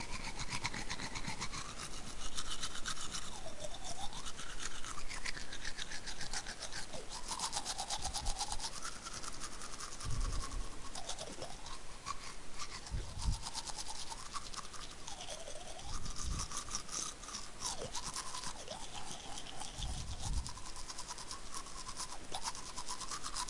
刷牙
描述：这是我用sm57刷牙的记录